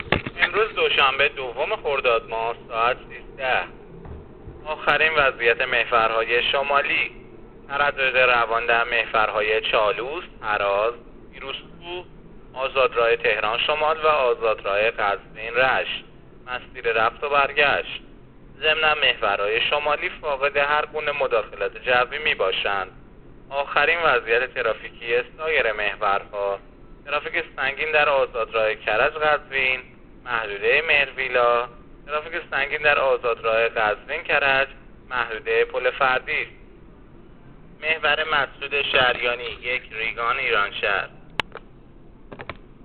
گزارش رادیو اینترنتی از آخرین وضعیت ترافیکی جاده‌ها تا ساعت ۱۳ دوم خرداد؛